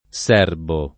[ S$ rbo ]